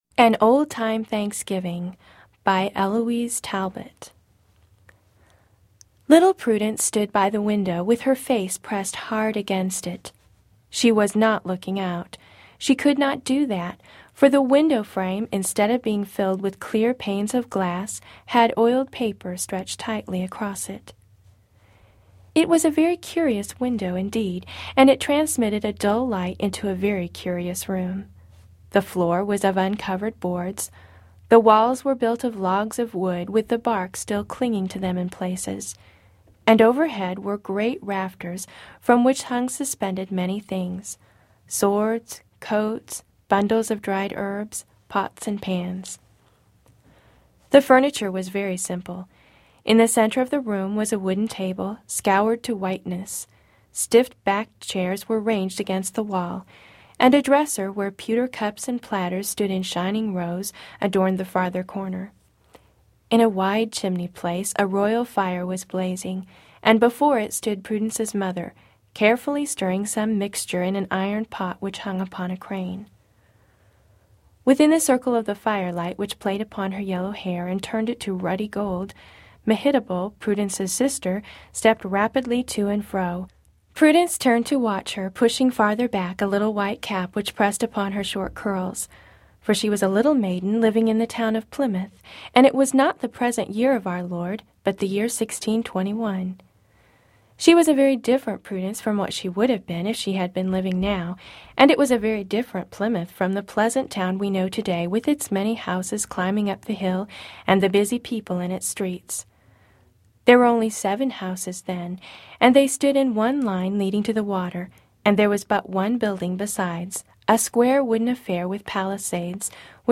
Purchase entire CD album of Children's Stories.